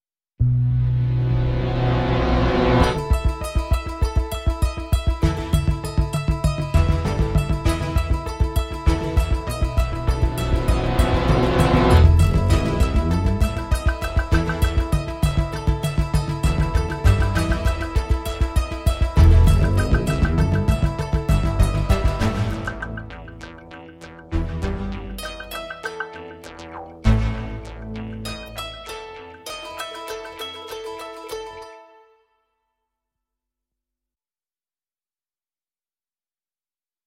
thème principal aux couleurs rustiques (violon et balalaïka)